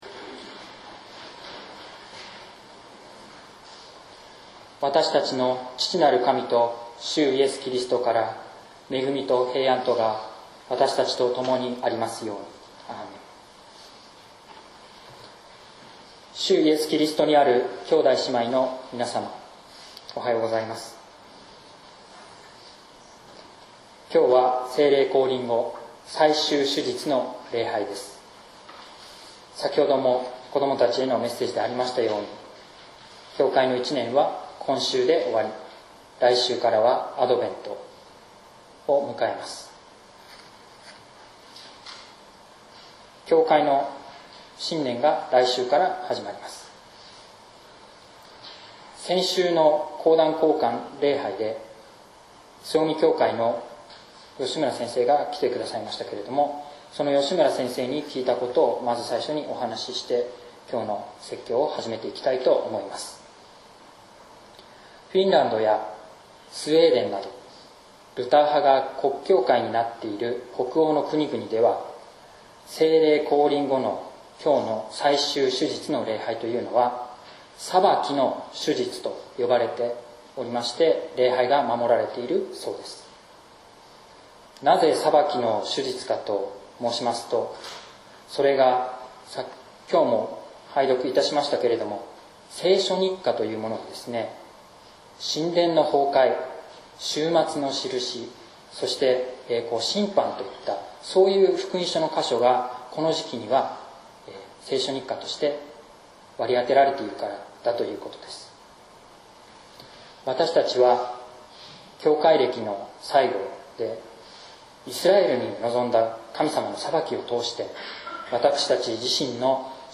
説教「新約時代の神殿」（音声版） | 日本福音ルーテル市ヶ谷教会